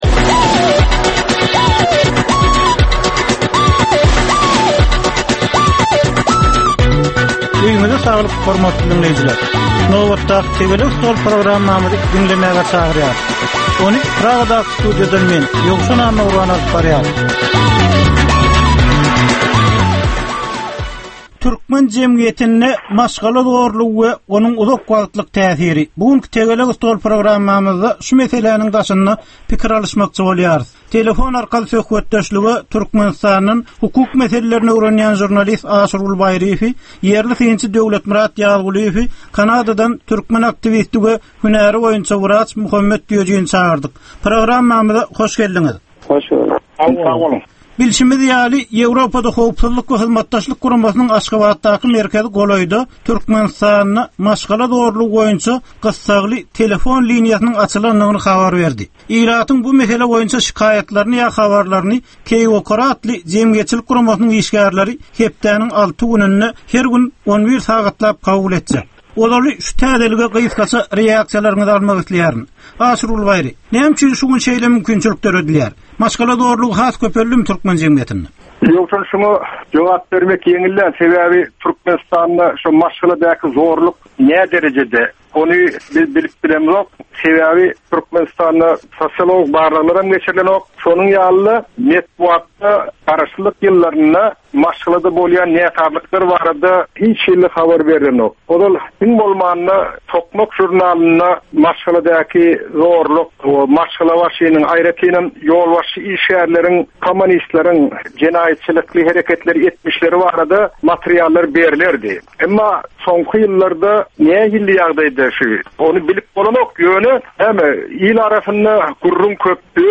Jemgyýetçilik durmuşynda bolan ýa-da bolup duran soňky möhum wakalara ýa-da problemalara bagyşlanylyp taýyarlanylýan ýörite Tegelek stol diskussiýasy. 30 minutlyk bu gepleşikde syýasatçylar, analitikler we synçylar anyk meseleler boýunça öz garaýyşlaryny we tekliplerini orta atýarlar.